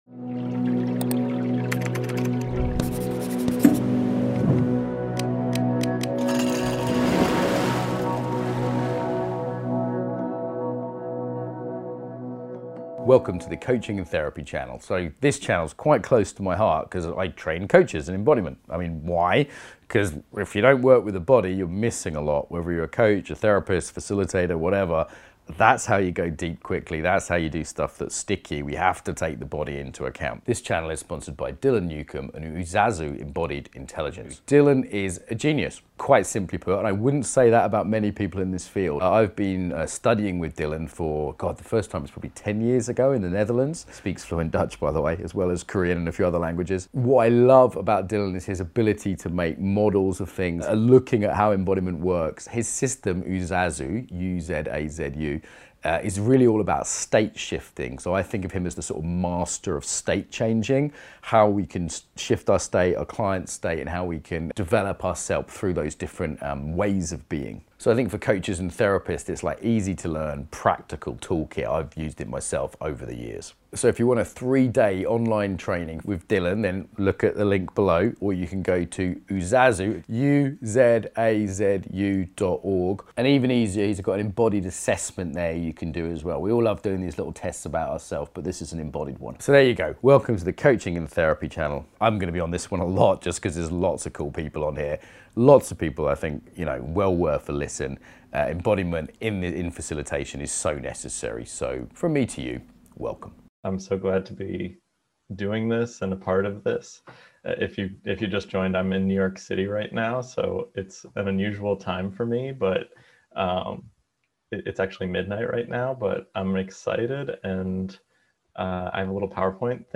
– C.G.Jung. This one hour workshop represents an introduction to tapping into the innate intelligence of the body in order to meaningfully work with the way family and ancestral history can be at the root of various barriers you’re facing.